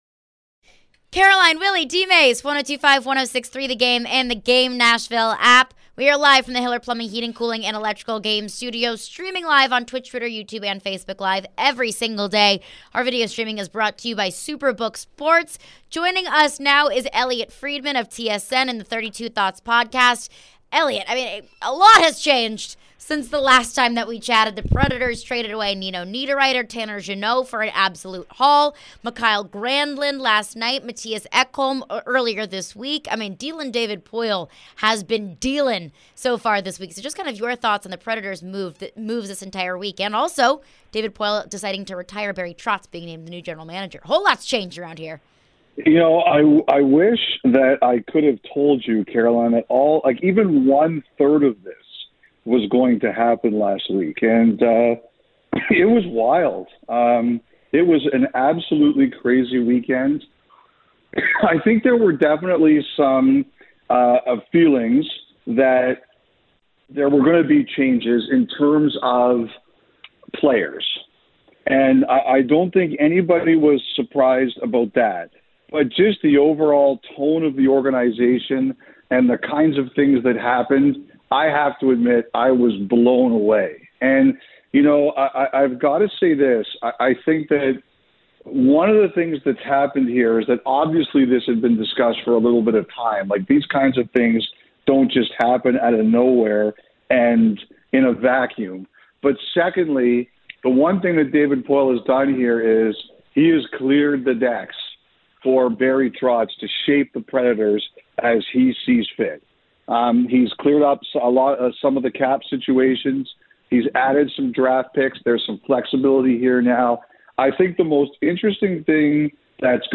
Elliotte Friedman Interview (3-2-23)